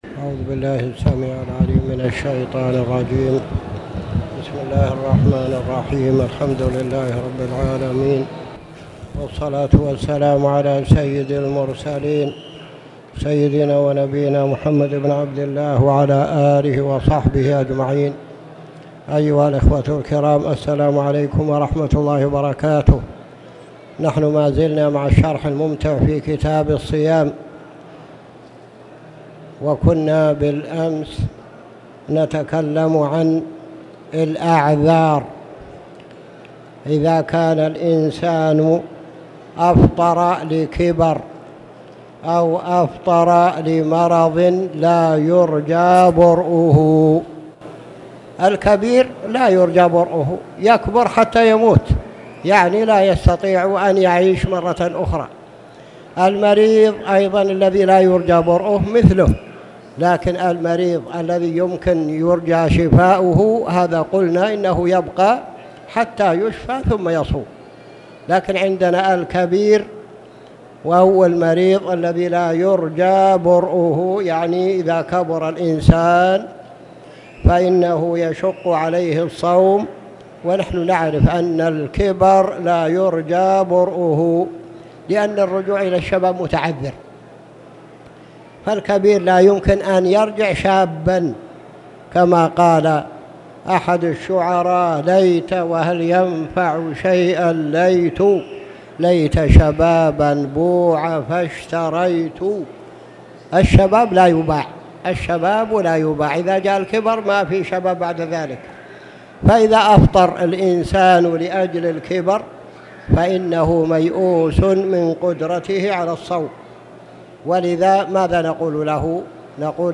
تاريخ النشر ٢١ رجب ١٤٣٨ هـ المكان: المسجد الحرام الشيخ